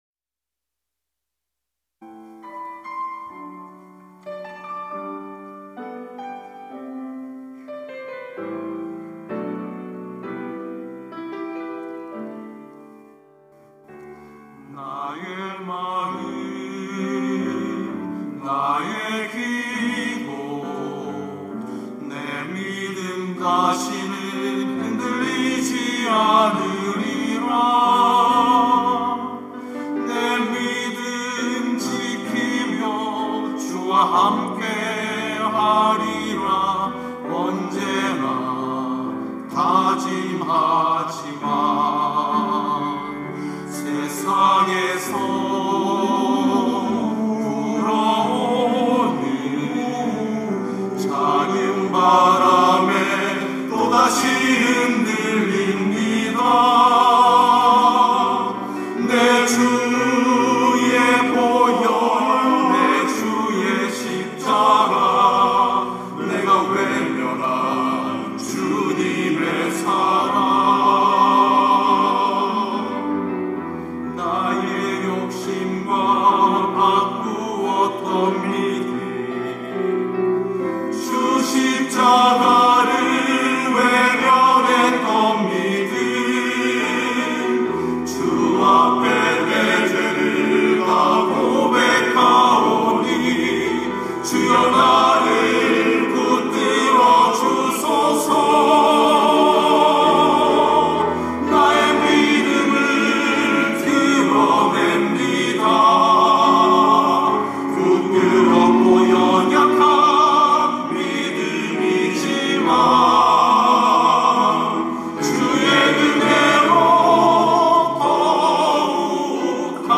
천안중앙교회
찬양대 휘오스